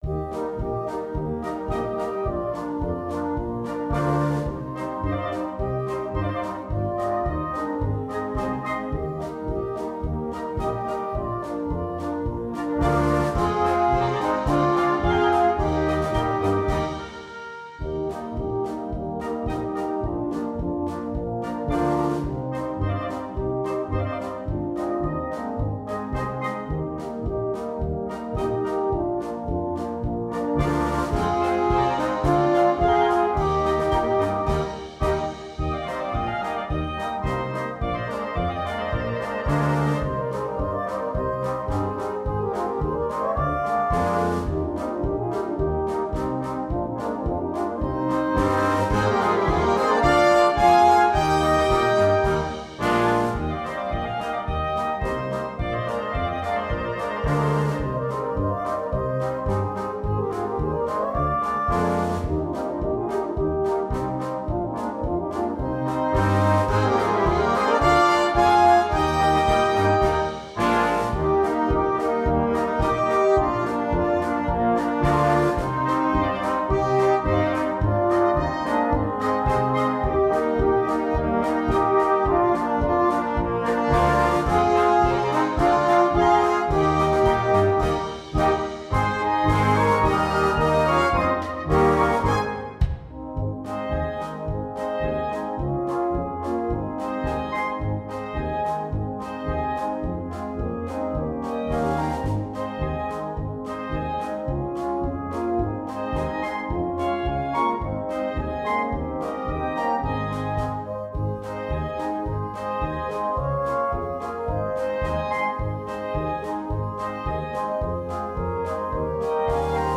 sans instrument solo